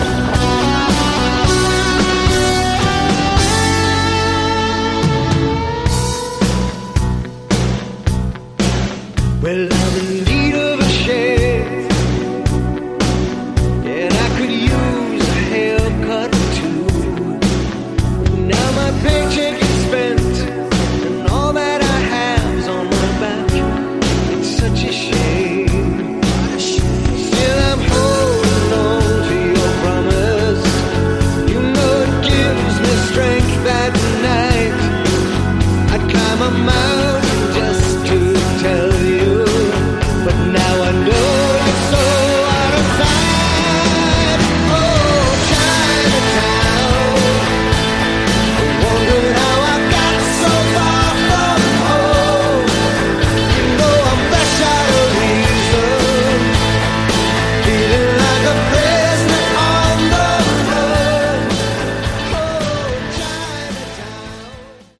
Category: AOR
I like the guitar solo quite a bit.